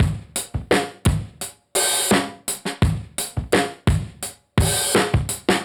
Index of /musicradar/dusty-funk-samples/Beats/85bpm/Alt Sound